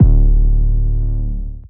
Major 808 2.wav